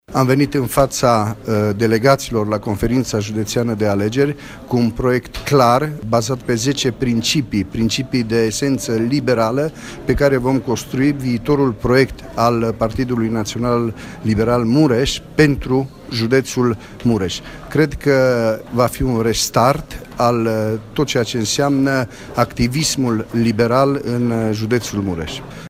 Președintele filialei Mureș a PNL, Cristian Chirteș, a declarat că dorește repornirea liberalismului în județ: